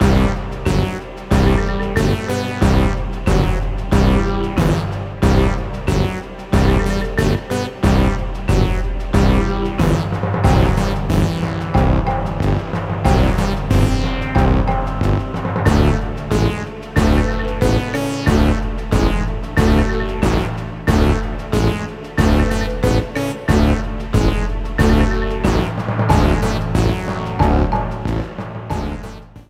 Ripped from the game
clipped to 30 seconds and applied fade-out
Fair use music sample